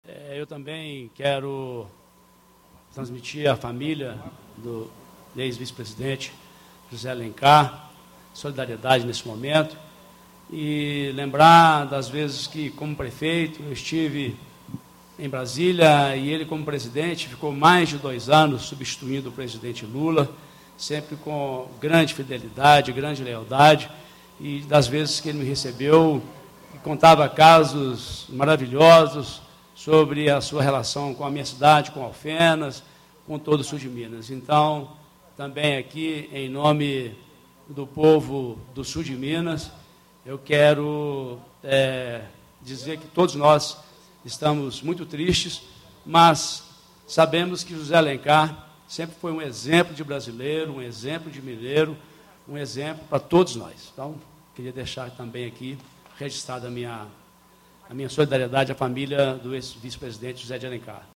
Reunião de Plenário - Pronunciamento sobre o ex-vice-presidente da república, José Alencar - Assembleia Legislativa de Minas Gerais